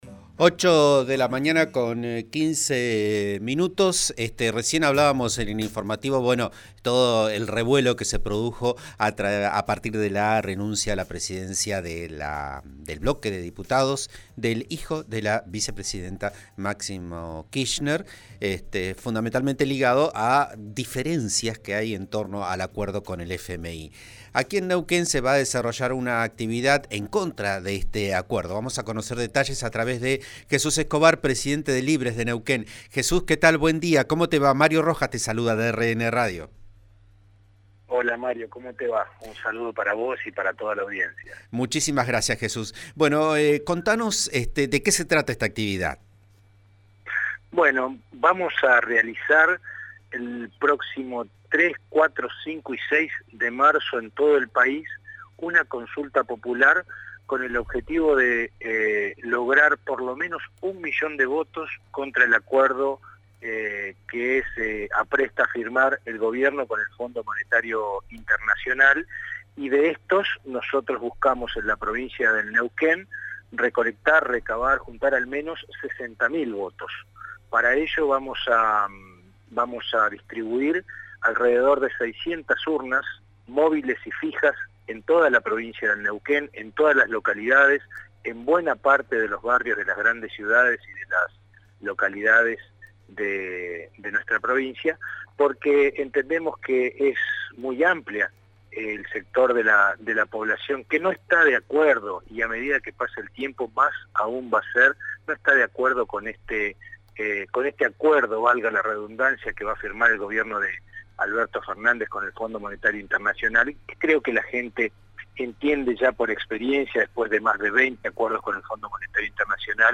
en declaraciones a Vos A Diario, RN Radio 89